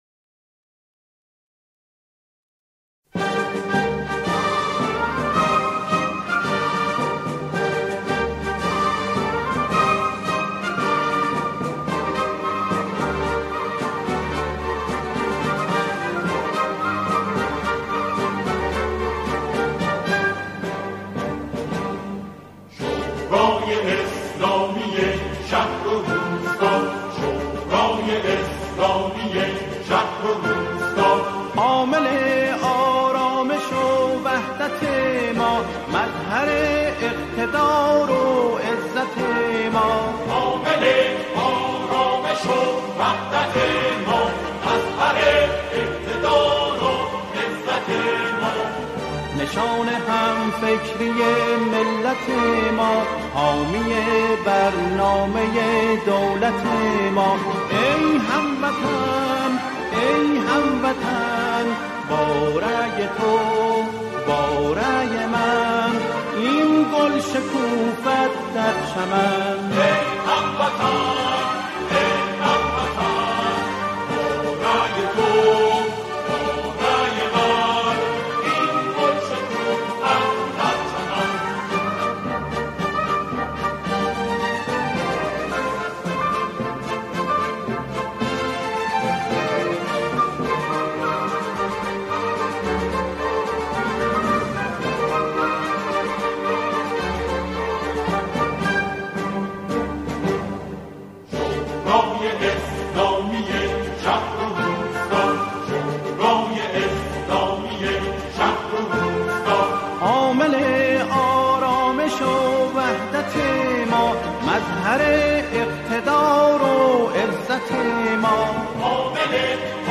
همخوانان، این قطعه را با شعری درباره انتخابات اجرا می‌کنند.